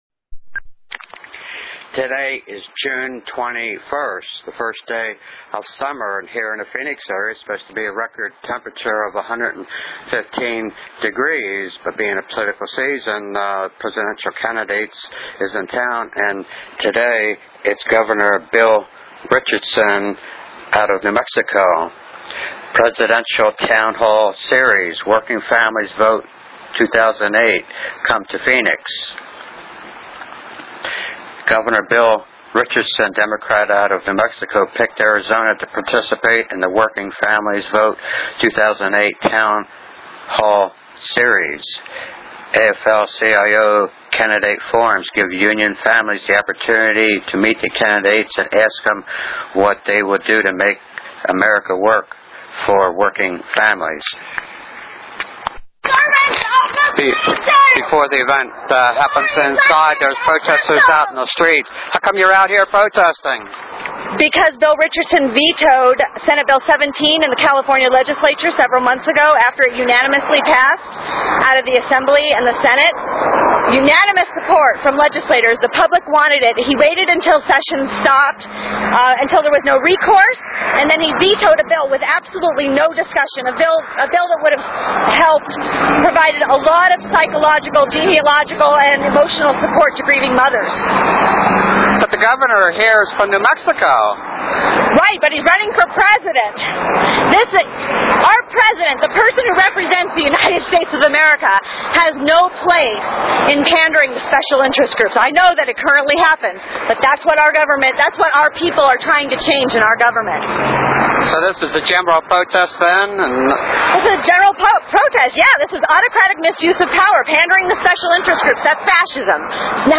Part One 54:25 minute Audio of presidential candidate Gov Bill Richardson D-NM visit to AZ AFL-CIO Union Hall on the campaign trial